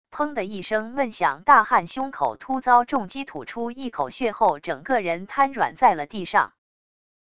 砰的一声闷响 大汉胸口突遭重击 吐出一口血后整个人瘫软在了地上wav音频